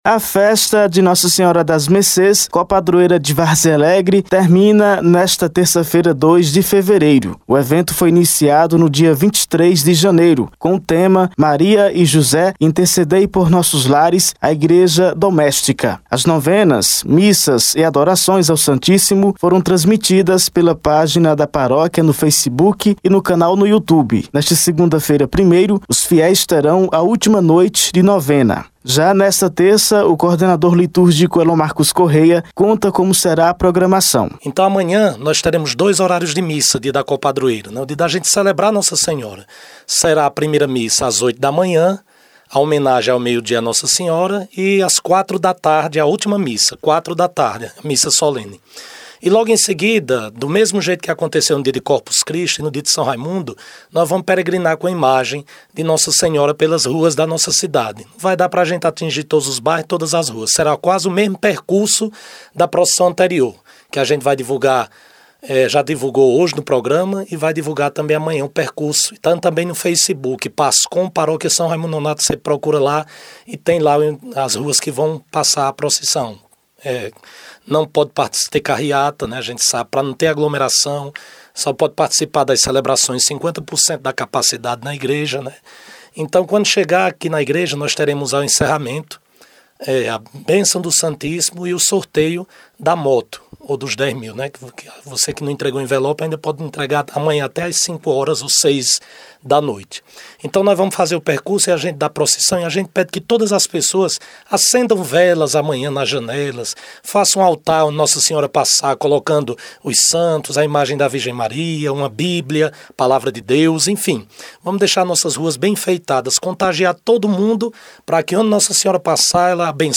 A festa de Nossa Senhora das Mercês, copadroeira de Várzea Alegre, termina nesta terça-feira, 02 de fevereiro. Acompanhe a matéria de áudio: